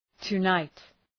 Προφορά
{tə’naıt}